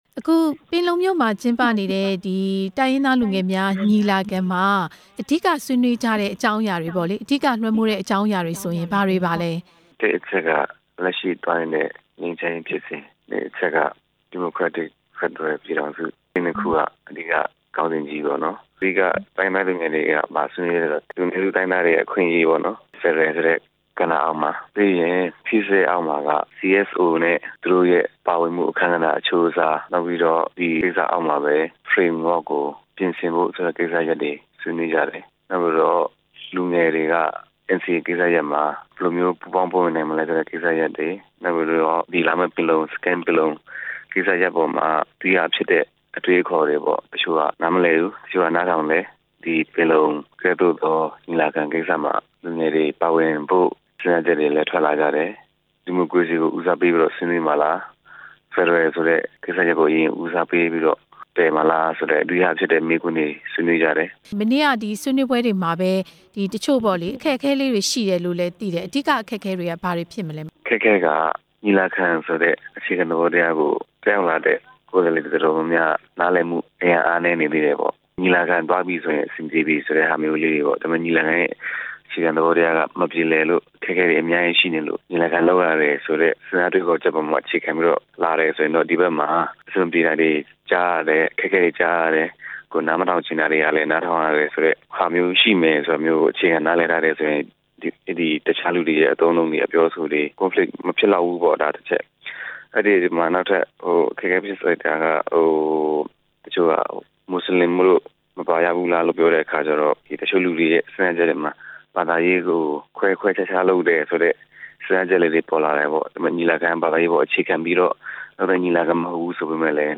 တိုင်းရင်းသား လူငယ်ညီလာခံက ဆွေးနွေးချက်တွေအကြောင်း မေးမြန်းချက်